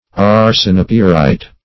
Arsenopyrite \Ar`sen*o*pyr"ite\, n. [Arsenic + pyrite.]